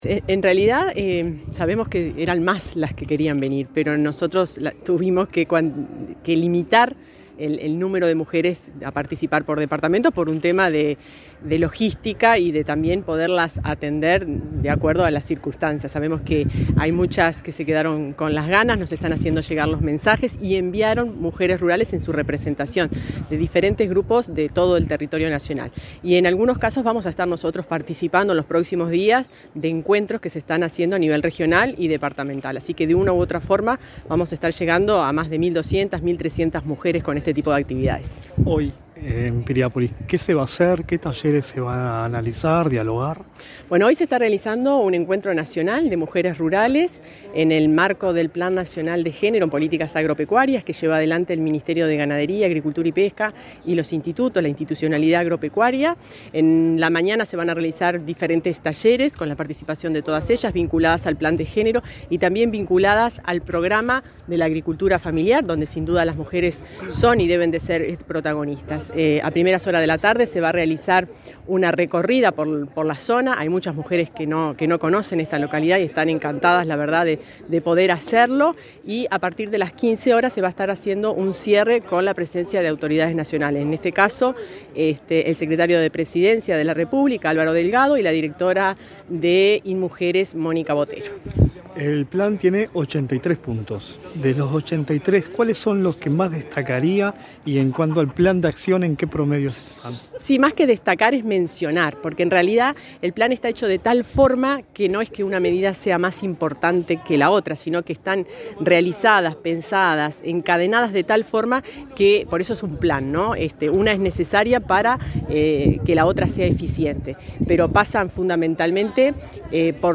Entrevista a la directora general del Ministerio de Ganadería, Fernanda Maldonado
Entrevista a la directora general del Ministerio de Ganadería, Fernanda Maldonado 13/10/2023 Compartir Facebook Twitter Copiar enlace WhatsApp LinkedIn La directora general del Ministerio de Ganadería, Fernanda Maldonado, dialogó con Comunicación Presidencial en Maldonado, donde se realizó, este viernes 13, un encuentro nacional de mujeres rurales del Plan Nacional de Género.